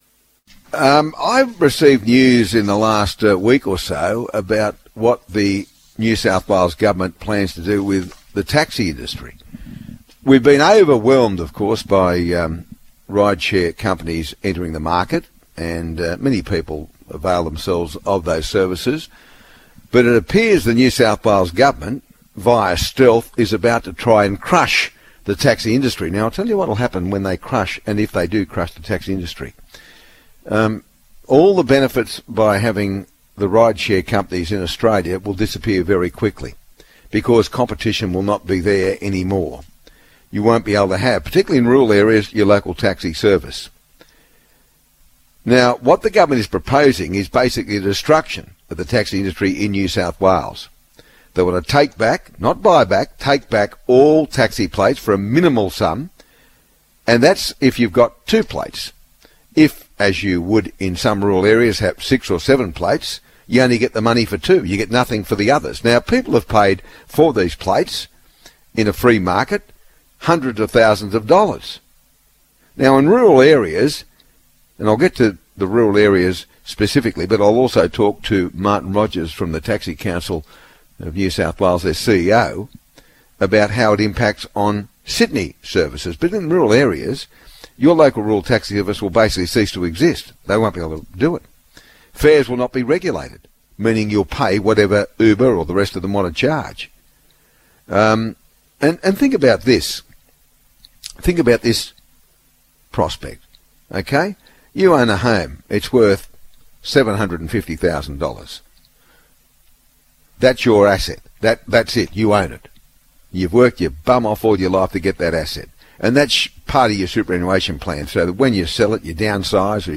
Please click on the play button below to listen to the extended version of the interview including NSW Taxi Licence Owners calling during the show to discuss their concerns Extended Version of the interview with Taxi Licence Owners in NSW on The Ray Hadley Morning Show